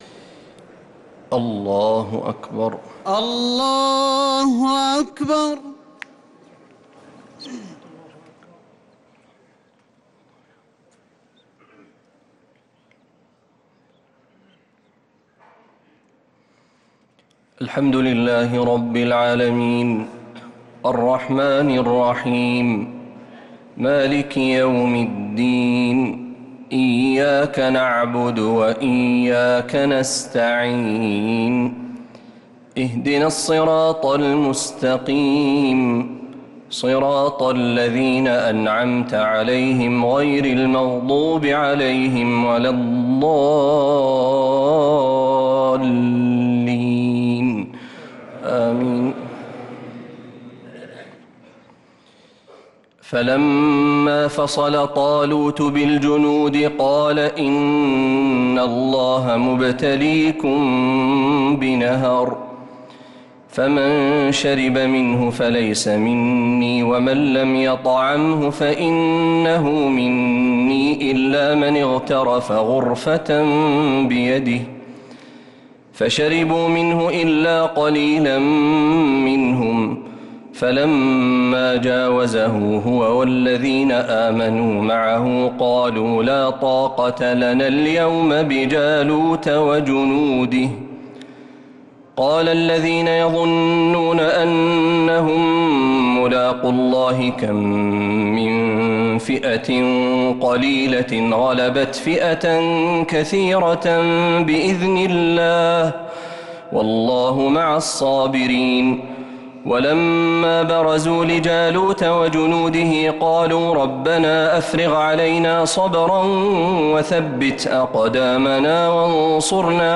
تراويح ليلة 3 رمضان 1446هـ من سورة البقرة {249-271} Taraweeh 3rd night Ramadan 1446H > تراويح الحرم النبوي عام 1446 🕌 > التراويح - تلاوات الحرمين